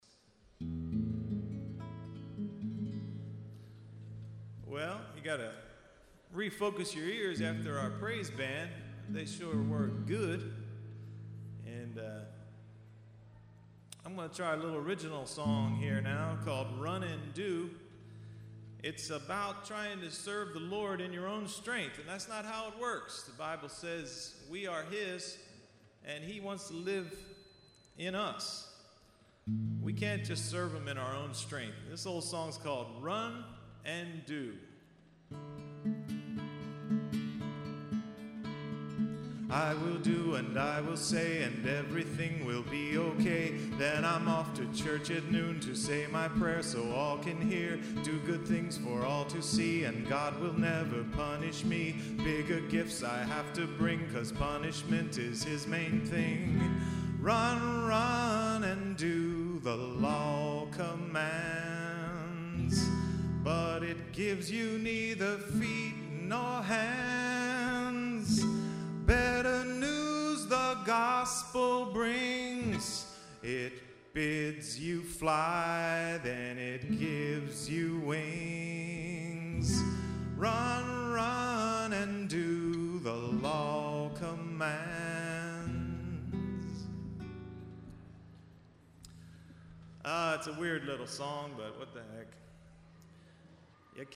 Voice and Guitar- Echelon Mall, Voorhees, NJ